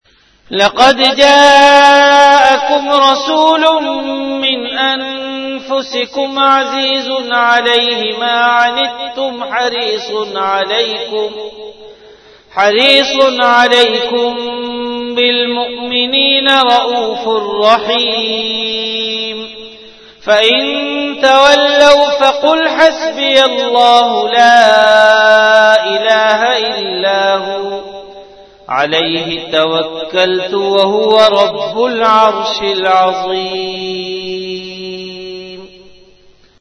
Tilawat · Jamia Masjid Bait-ul-Mukkaram, Karachi
CategoryTilawat
VenueJamia Masjid Bait-ul-Mukkaram, Karachi
Event / TimeAfter Isha Prayer